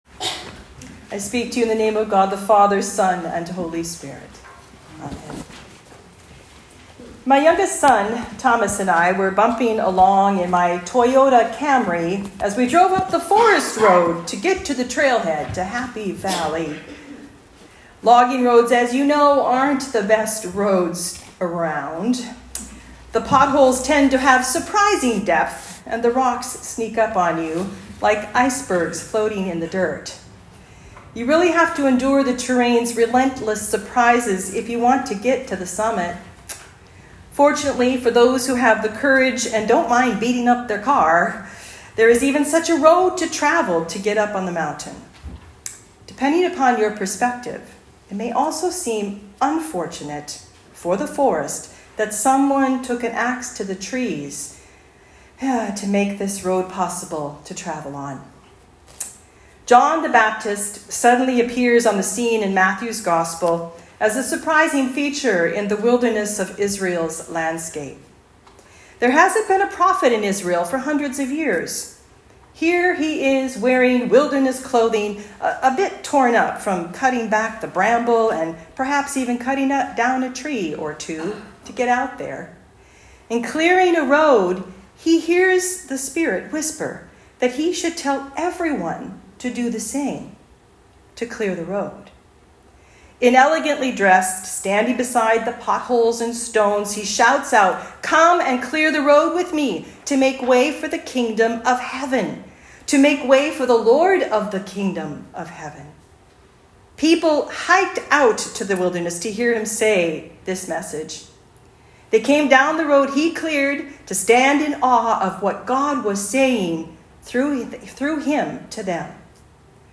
Advent 2 Talk